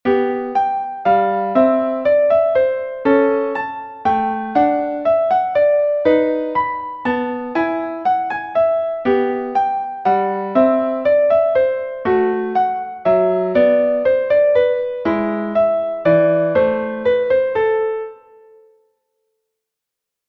Schusterfleck, Rosalie, Spottname für die mehrere Male gleich nacheinander erfolgende Versetzung oder Rückung einer Melodie auf die nächsthöhere oder tiefere Tonstufe.